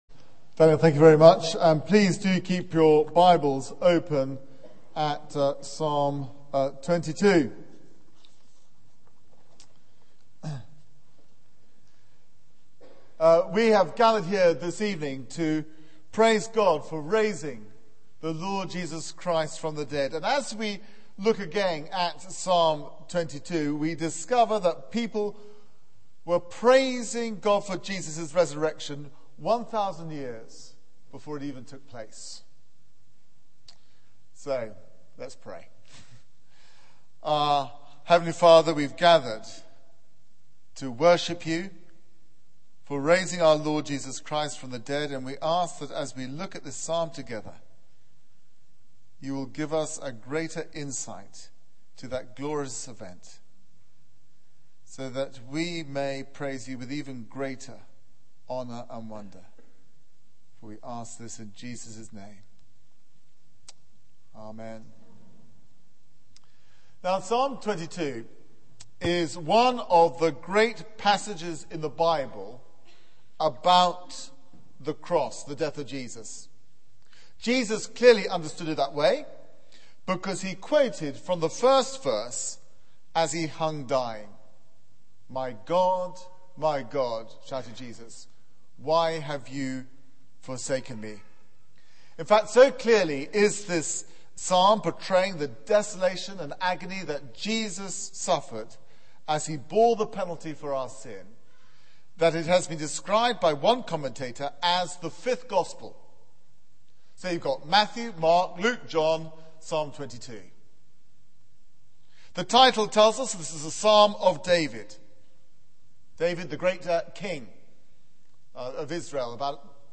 Media for 6:30pm Service on Sun 04th Apr 2010 18:30 Speaker
Sermon Search the media library There are recordings here going back several years.